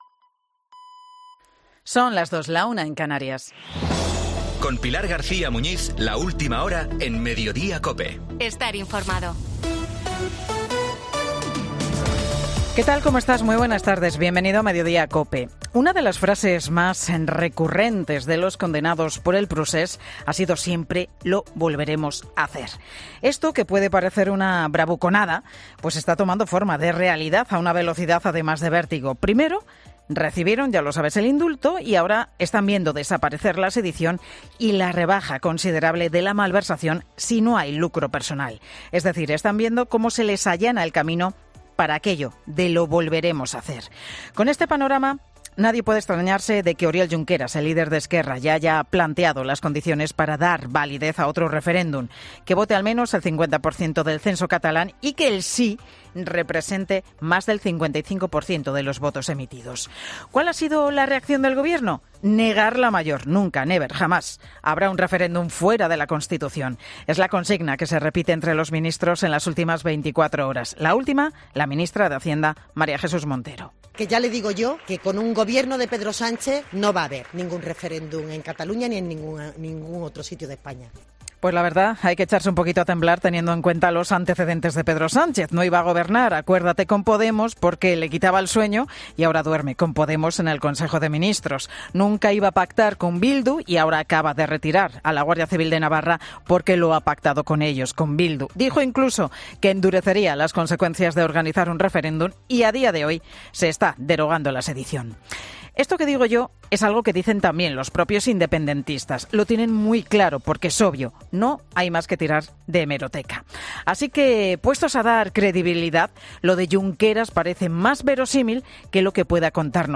AUDIO: Escucha el monólogo de Pilar García Muñiz en 'Mediodía COPE'